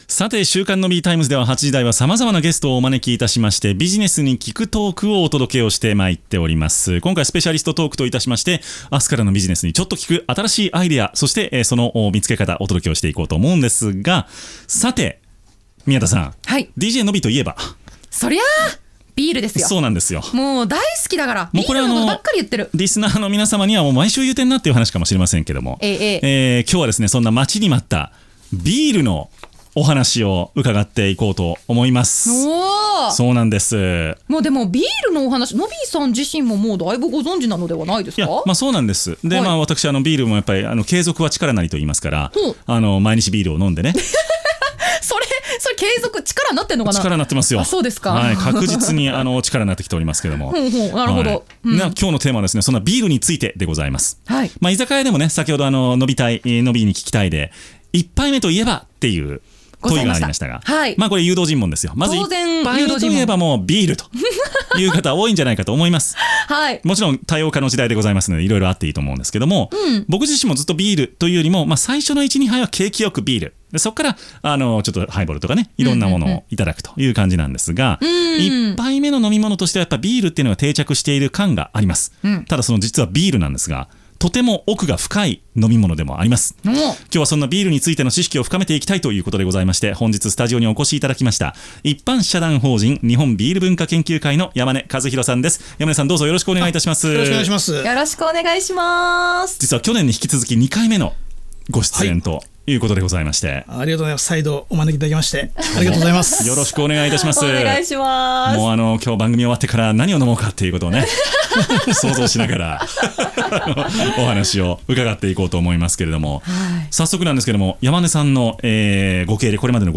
ビールにまつわる話題や、ビア検の取り組みについて、パーソナリティとのトークを通じて紹介しています。